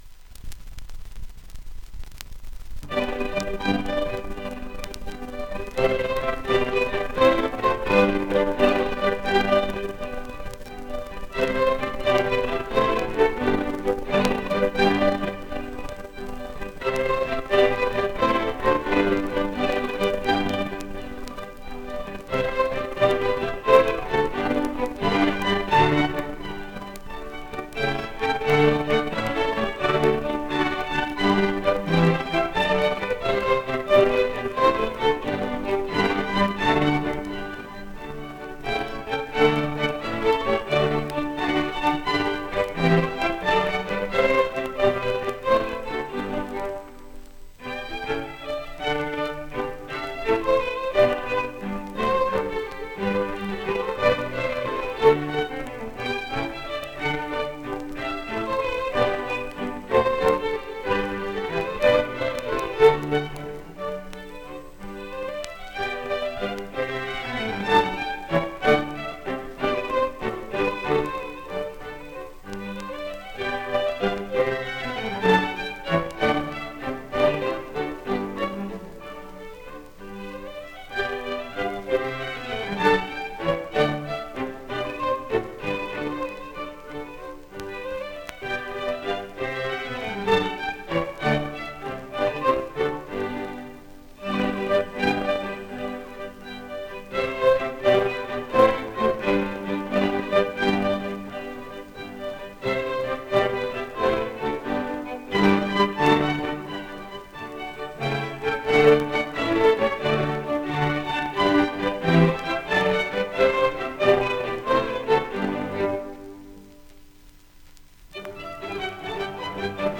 2 discos : 78 rpm ; 30 cm Intérpretes: Arthur Fiedler, conductor.
harpsichordist.